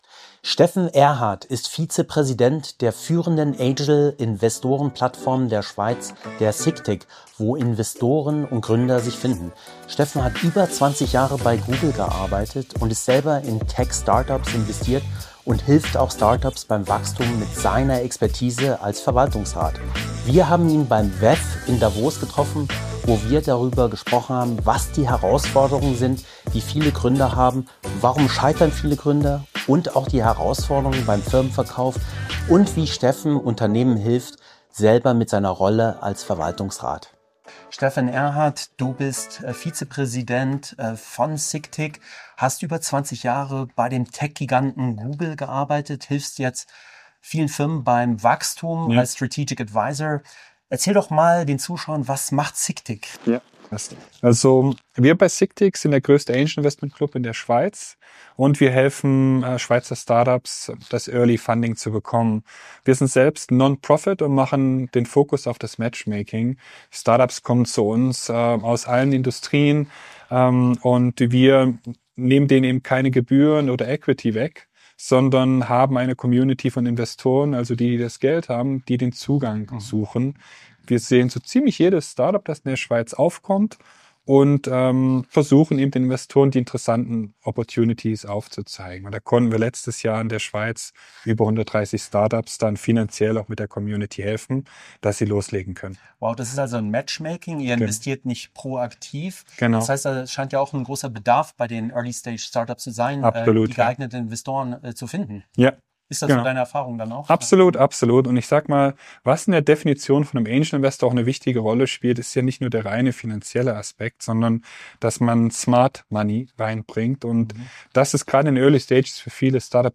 Interview
Die Episode wurde im Rahmen des World Economic Forum in Davos aufgezeichnet und richtet sich an Gründer:innen, Investor:innen und alle, die das europäische Startup-Ökosystem verstehen und mitgestalten wollen.